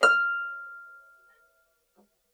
KSHarp_F6_mf.wav